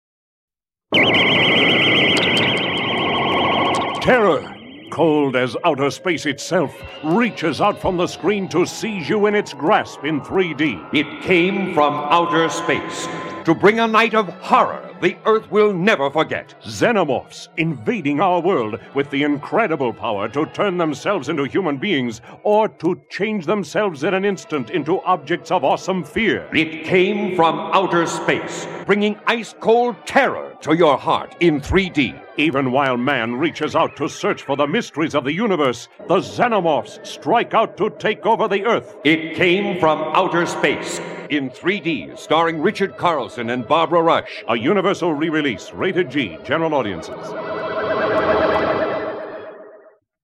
1972 3D Radio Spots